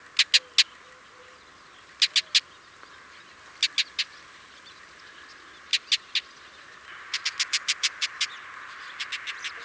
Außerdem verfügen Schwarzstirnwürger über ein reiches Repertoire an Rufen. Die meisten klingen krächzend und rau.
call1.wav